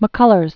(mə-kŭlərz), Carson Smith 1917-1967.